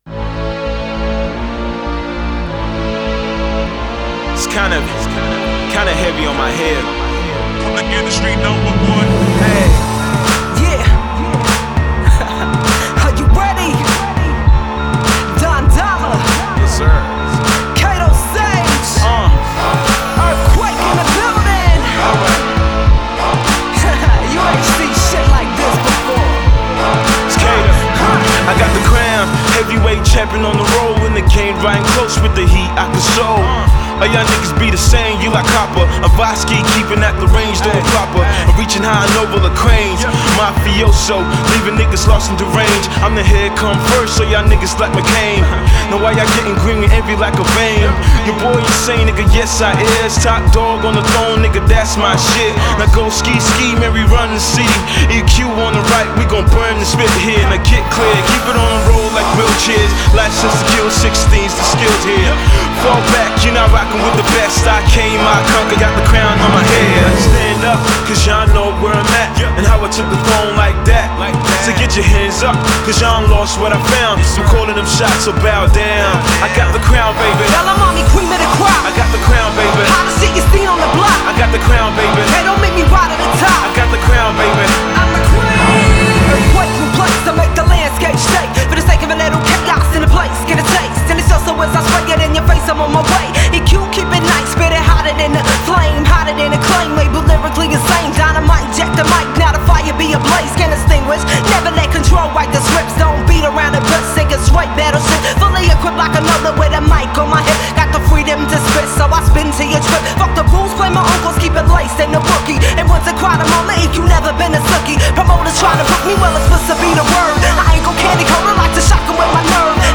R&B, Hip Hop, Funk, Soul and Rock
talk-box skills that only get better by the song